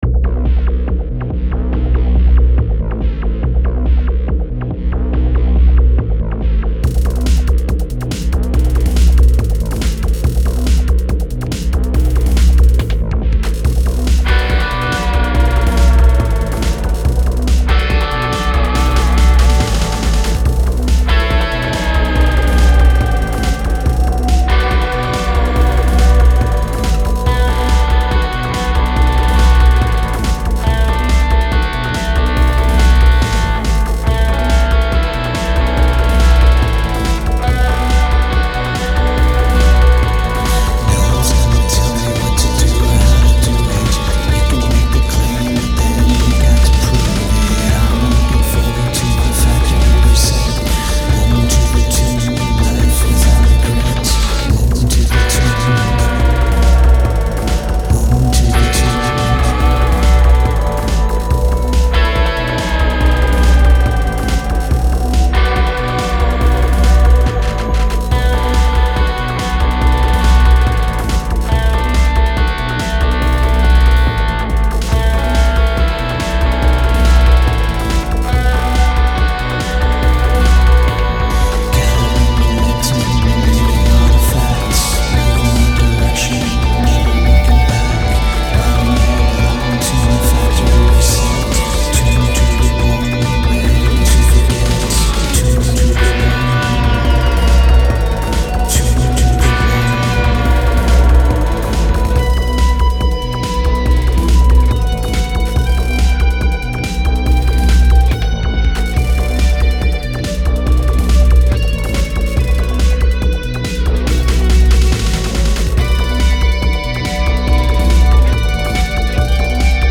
Must include at least one mood shift (musical & lyrical)